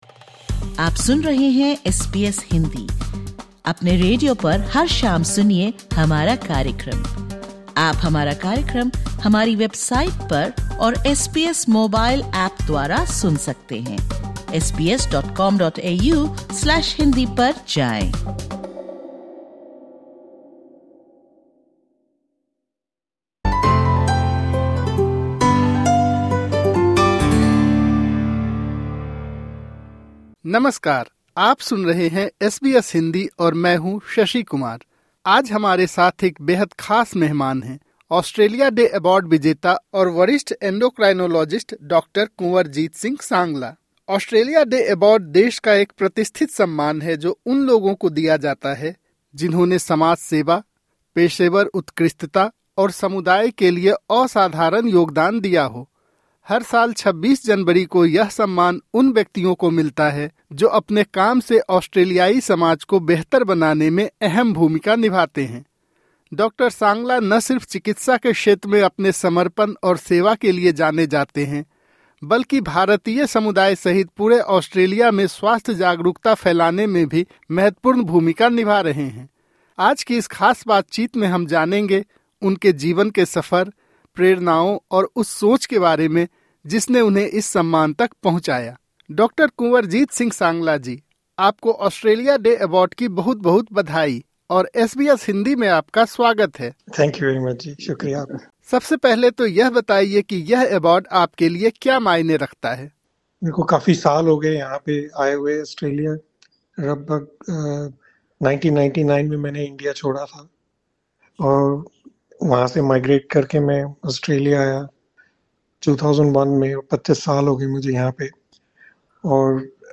इस साक्षात्कार में उन्होंने अपनी प्रवासी पृष्ठभूमि, शुरुआती संघर्षों, परिवार और समुदाय से मिले समर्थन, तथा उन मूल्यों पर रोशनी डाली, जिन्होंने उन्हें एक डॉक्टर और एक इंसान के रूप में आकार दिया।